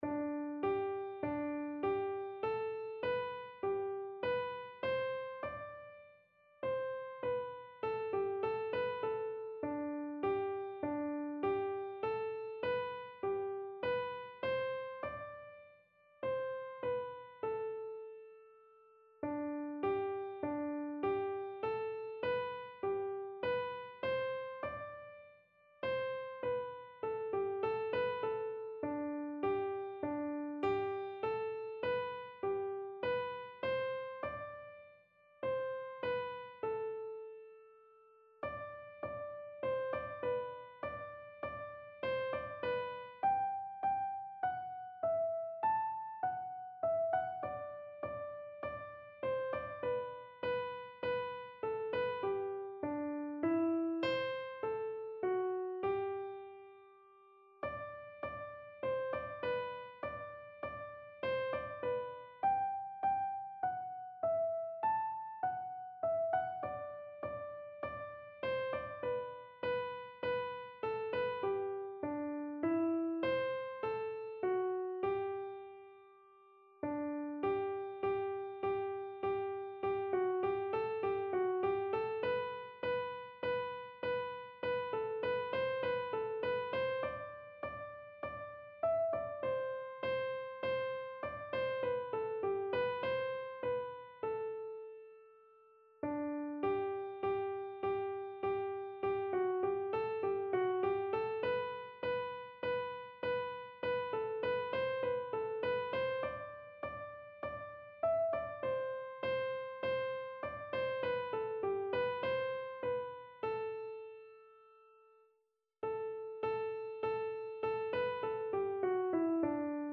Click any page number to hear the tunes on that page, played slowly so you can learn them.